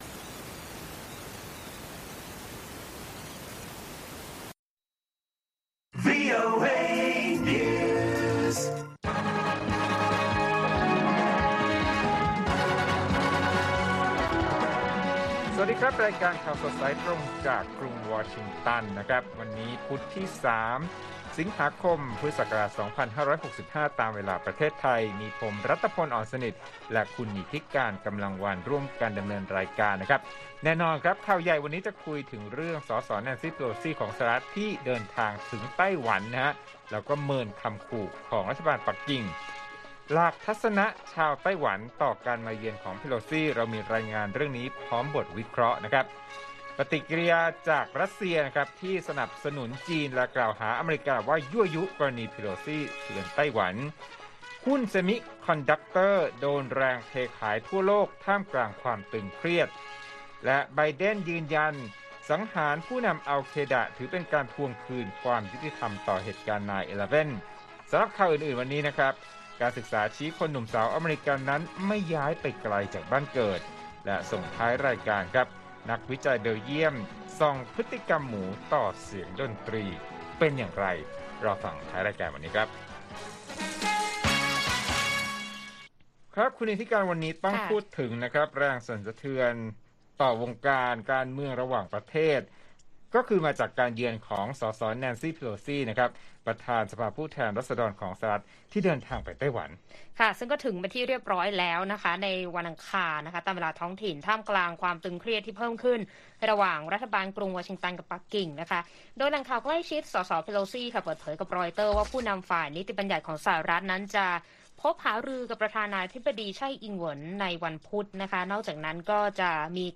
ข่าวสดสายตรงจากวีโอเอไทย 8:30–9:00 น. วันที่ 3 ส.ค. 65